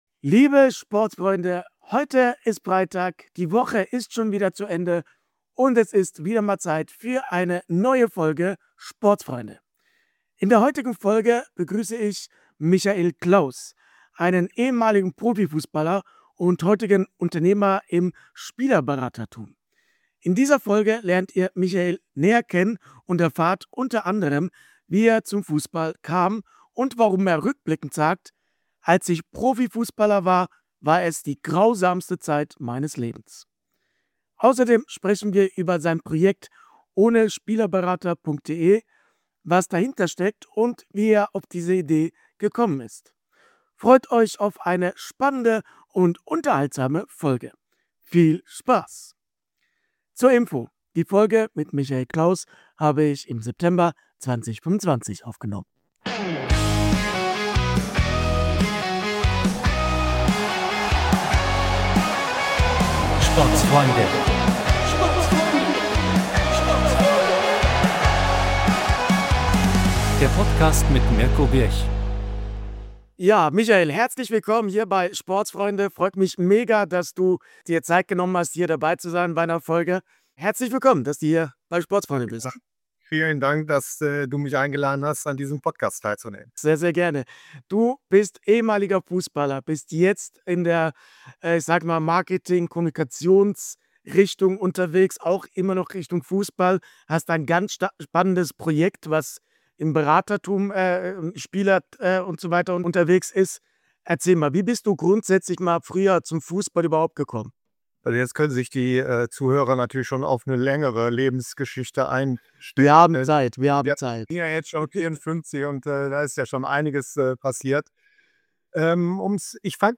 Zum Ende der Woche gibt es ein Gespräch, das den Fussball aus einer völlig anderen Perspektive zeigt.